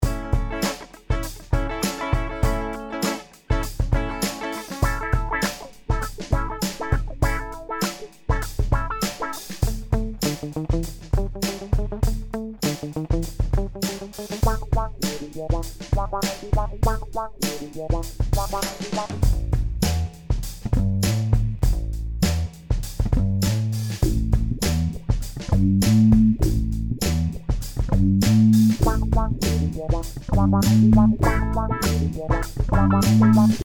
Analog Envelope Filter
Whether it’s guitar, bass, clavinet, orsynths, the MU-TRON III will help you get the vintage funk out.
Mu-Tron III Demo Clip